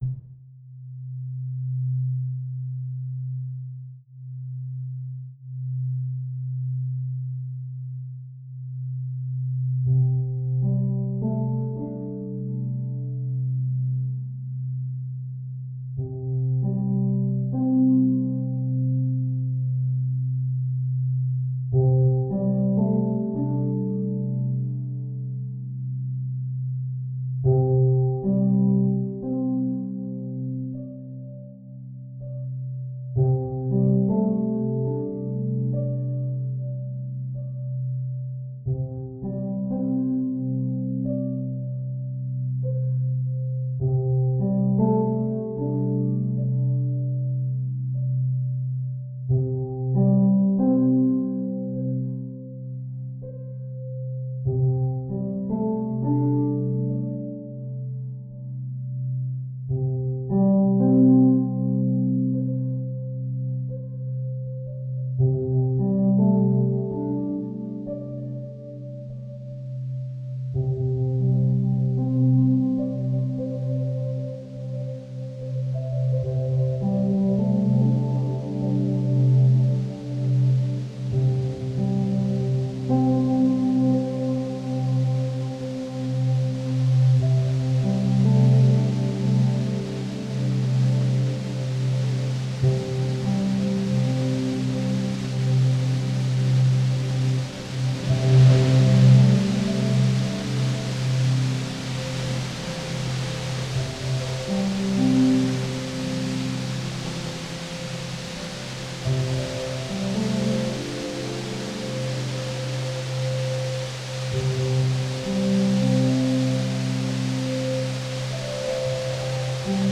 地球の何処かから聴こえる低音の元で抑制的なピアノが弾かれ、外からやってきた雨風の音が世界を包み込んでいくような雰囲気になりましたね。
今回のミックスで、デジタルリバーブを少し加えてみました。
Ambient-mix.wav